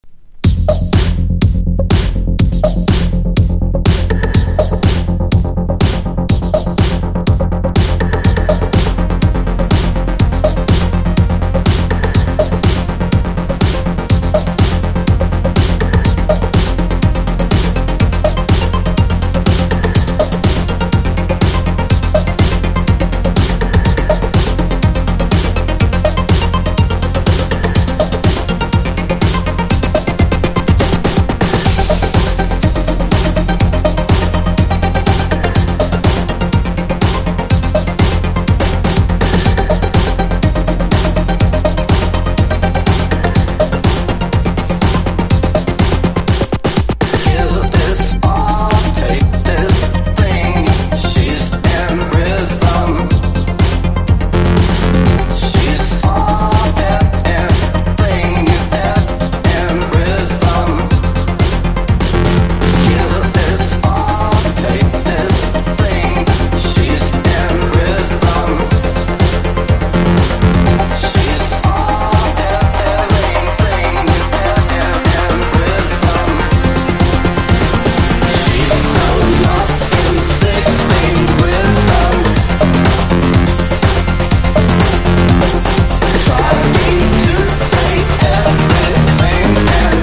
INDIE DANCE
ELECTRO